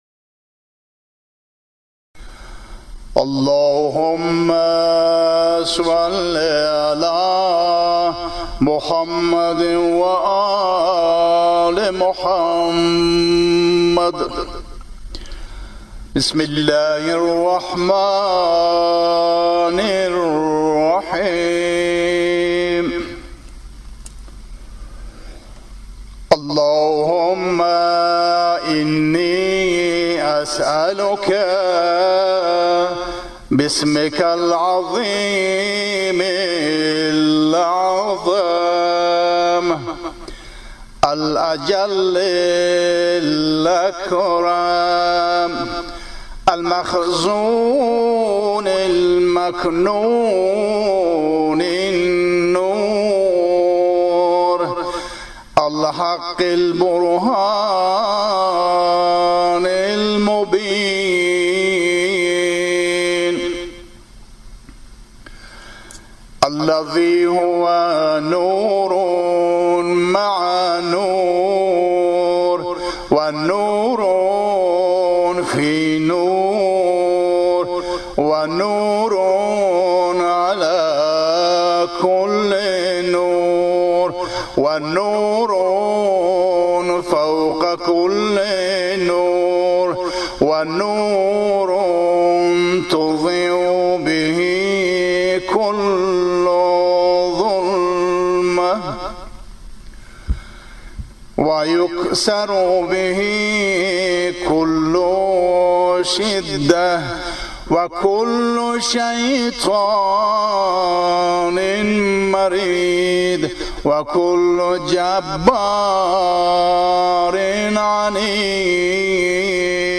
فایل صوتی دعای سریع الاجابه امام علی